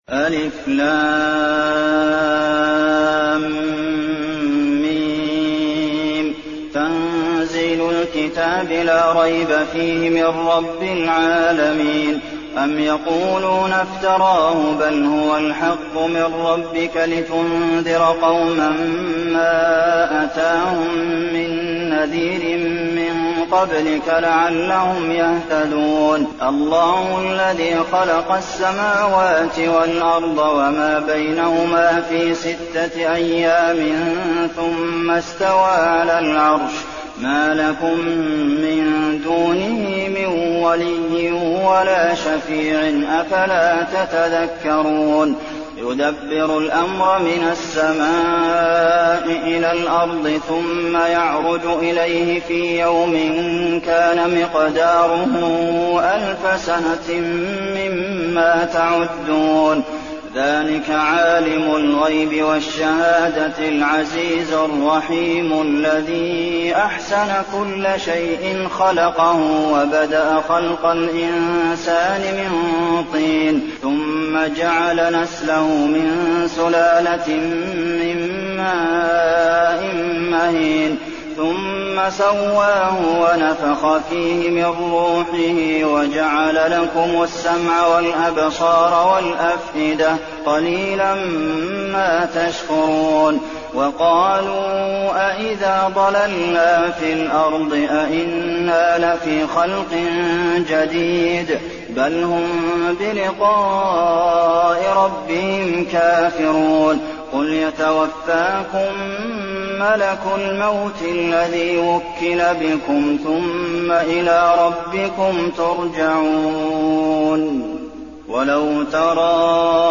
المكان: المسجد النبوي السجدة